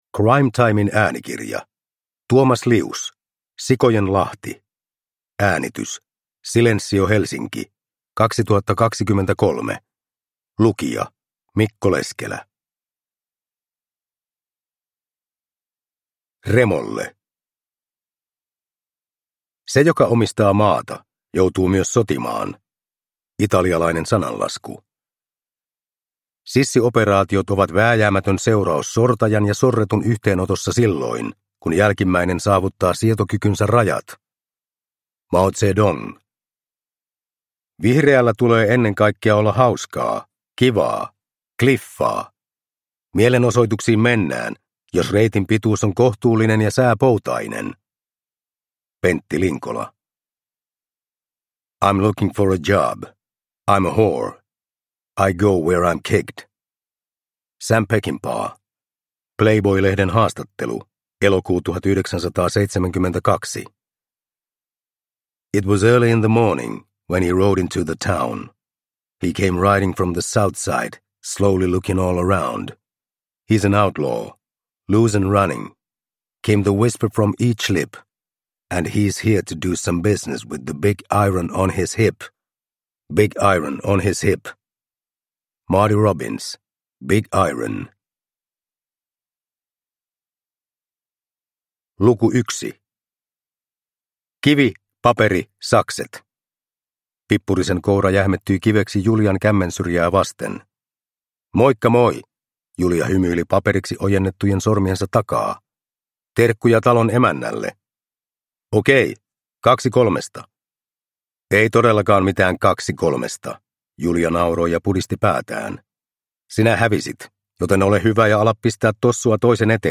Sikojen lahti – Ljudbok – Laddas ner